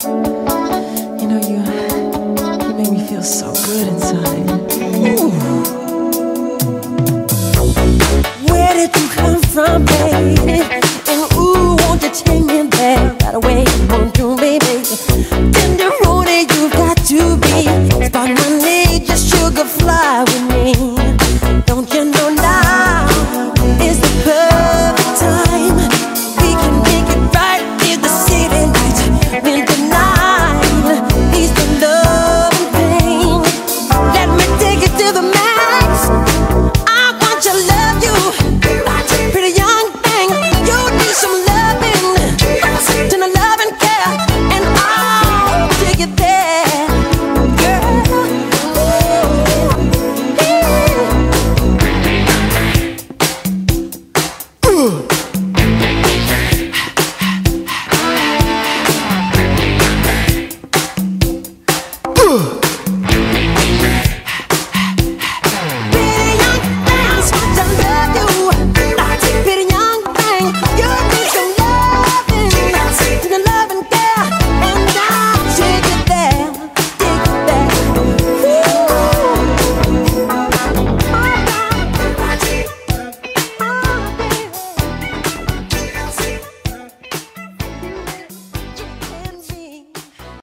BPM127-127
Audio QualityPerfect (Low Quality)